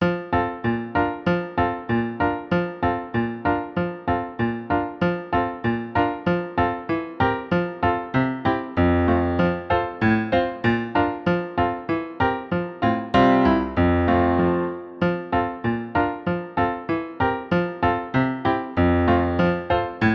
Tempo 96